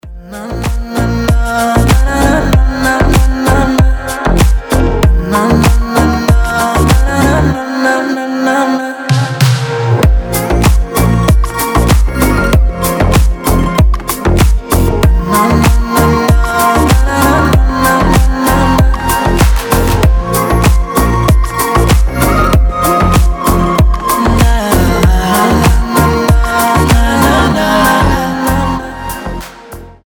• Качество: 320, Stereo
восточные мотивы
Dance Pop
легкие
Deep pop music